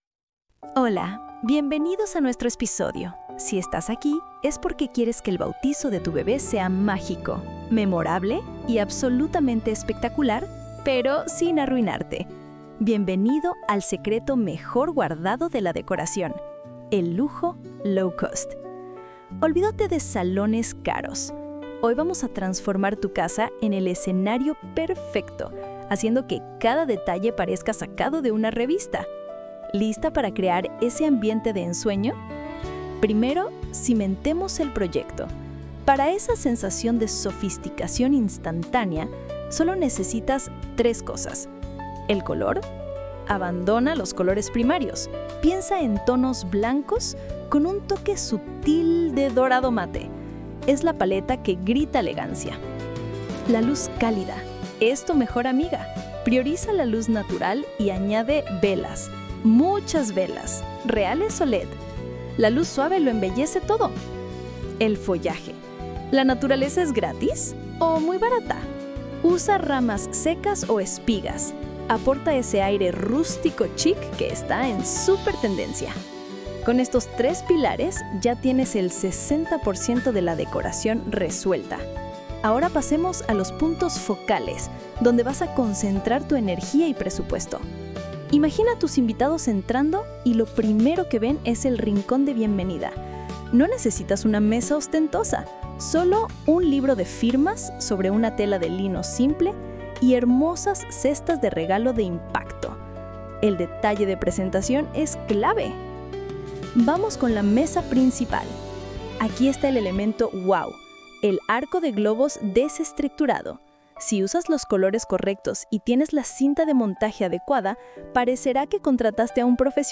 Audio Guía: Decoración Bautizo Económico y Sofisticado